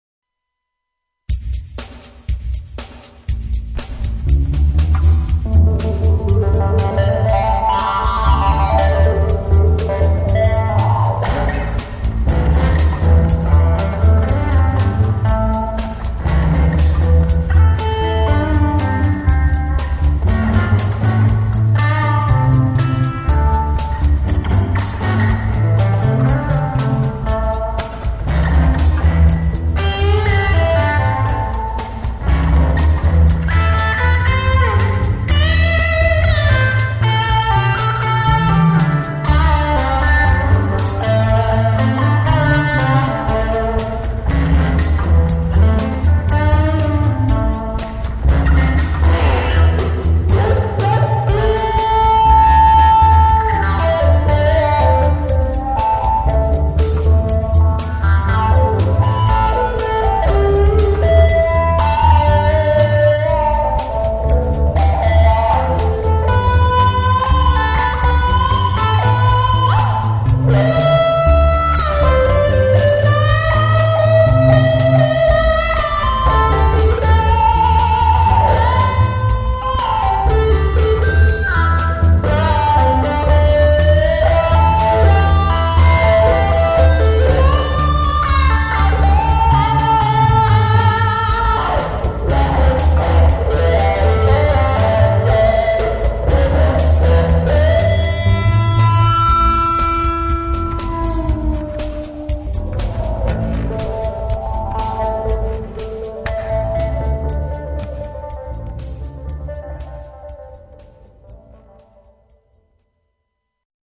Sehr stylisch, das ganze!
: ich hatte das noch im Kopf (späte 60er, "creamy" und "Power-Trio" waren natürlich tolle Stichworte da zündete was bei mir) und wollte versuchen das irgendwie umzusetzen.
: 1... 2... 3... und dann "BOAH!!! WAS FÜR EIN HALL!!!
: PS: das Wah, welches Ihr hier hört, ist noch original aus den 60ern, das ist mein altes Colorsound Wah